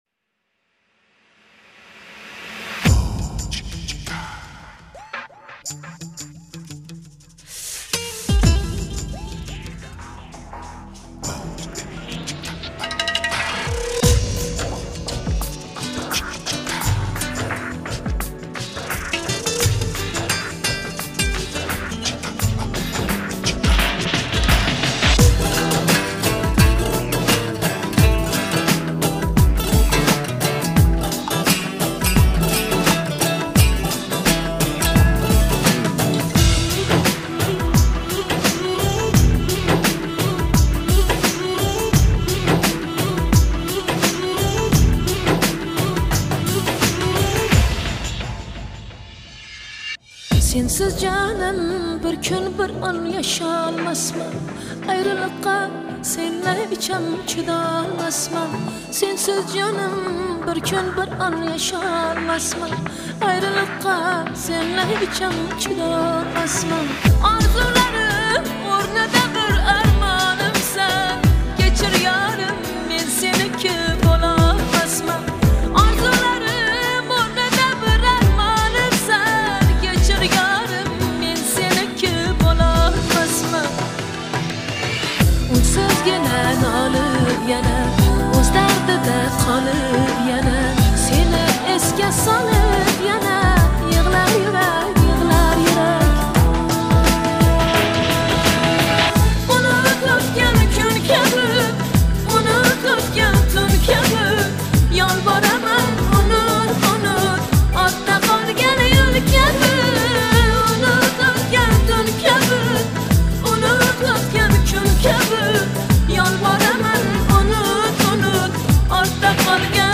это трек в жанре поп с элементами электронной музыки
отличается гармоничными мелодиями и выразительным вокалом